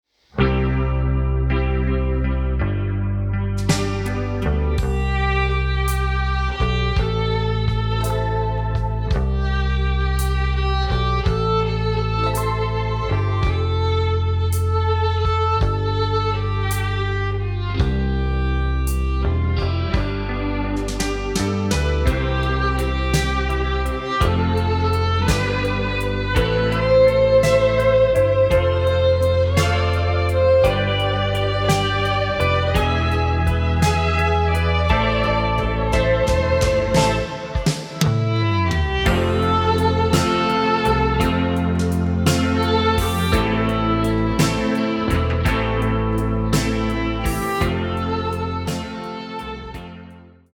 Violine